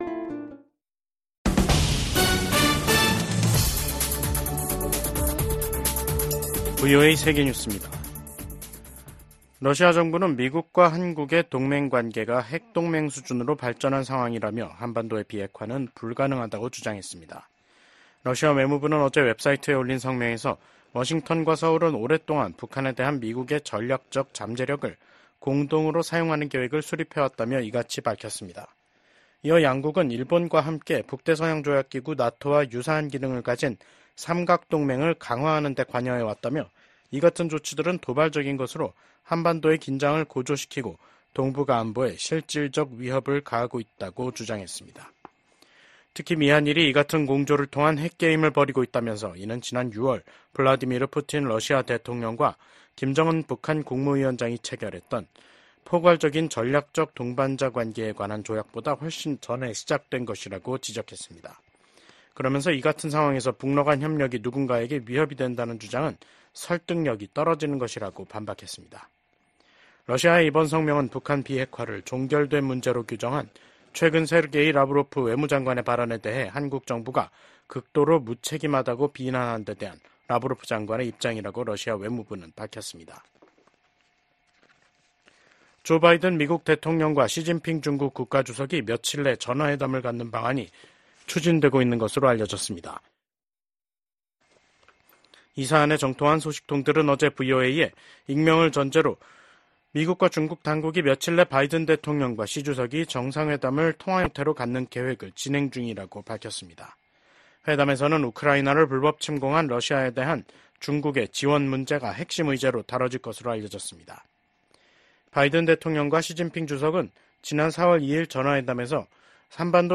VOA 한국어 간판 뉴스 프로그램 '뉴스 투데이', 2024년 10월 3일 2부 방송입니다. 미국 국무부의 커트 캠벨 부장관은 러시아 군대의 빠른 재건이 중국, 북한, 이란의 지원 덕분이라고 지적했습니다. 조 바이든 미국 대통령이 새로 취임한 이시바 시게루 일본 총리와 처음으로 통화하고 미한일 협력을 강화와 북한 문제를 포함한 국제 정세에 대응하기 위한 공조를 재확인했습니다.